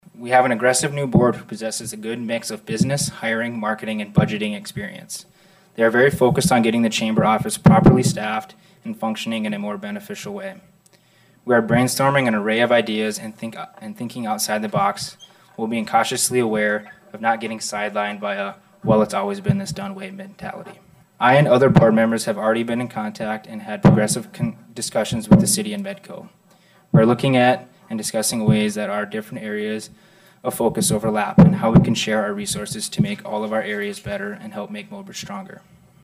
addressed the council during public forum about their concerns and about the direction of the Chamber moving forward.